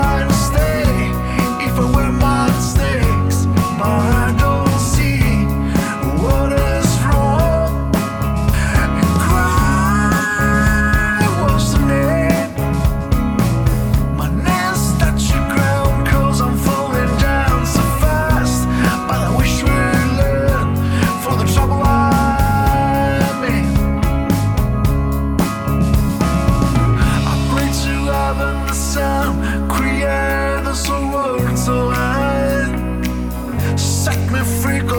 Жанр: Музыка из фильмов / Саундтреки
# TV Soundtrack